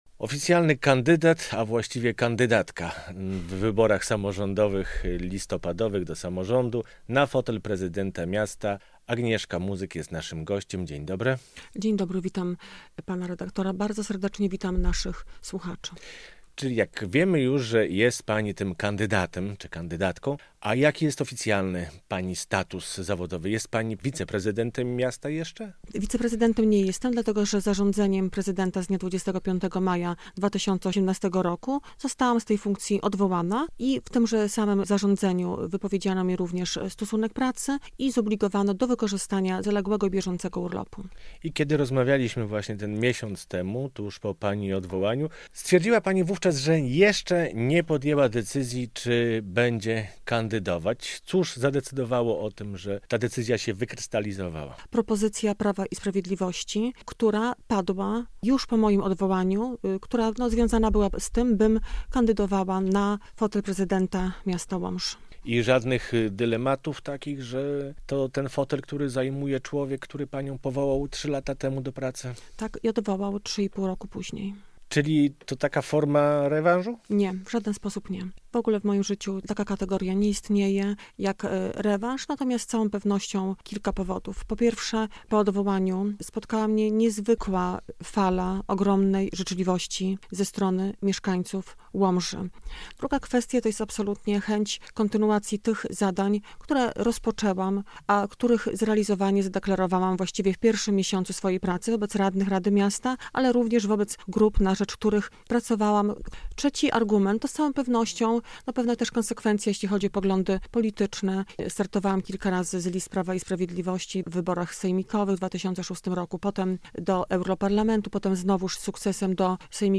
Radio Białystok | Gość | Agnieszka Muzyk - kandydatka PiS na prezydenta Łomży